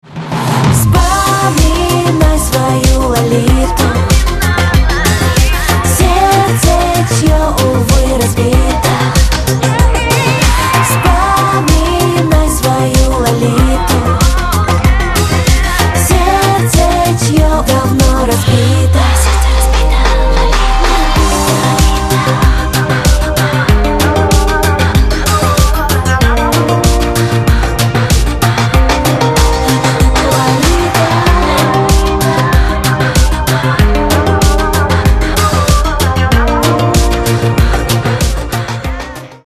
поп
женский вокал
dance
русская попса